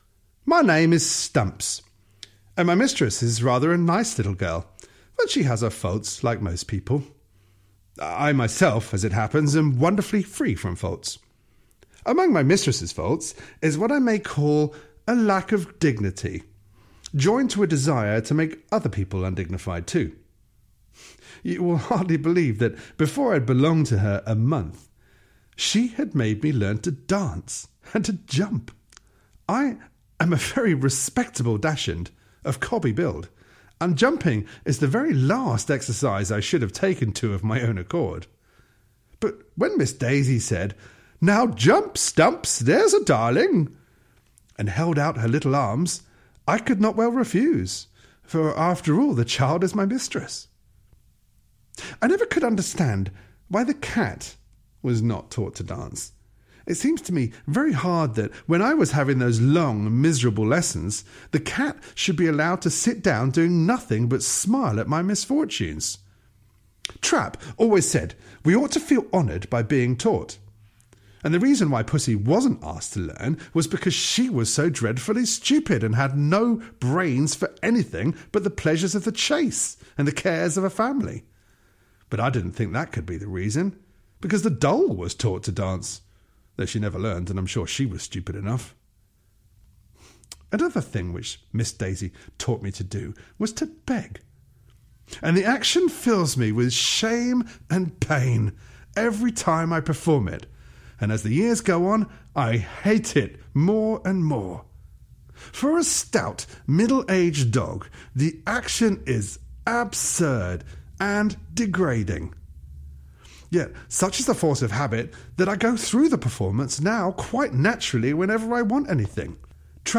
Doggy Tales (EN) audiokniha